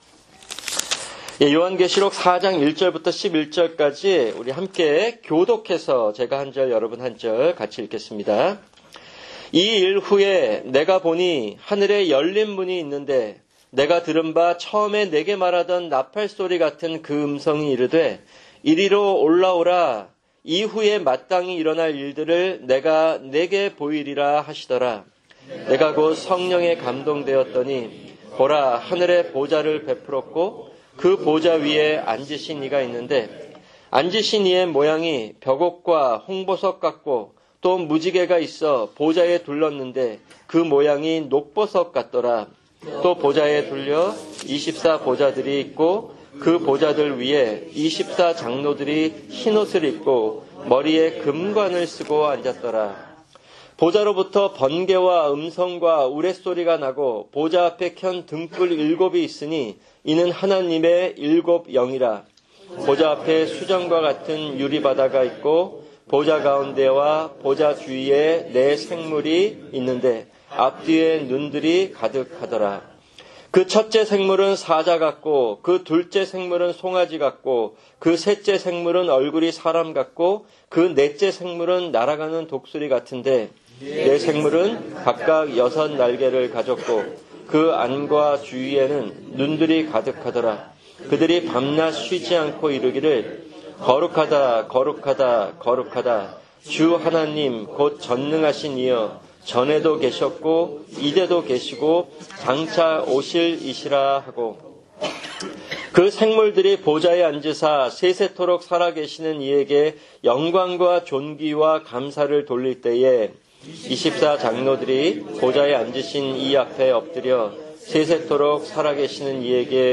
[금요 성경공부] 계시록 4:1-11(3)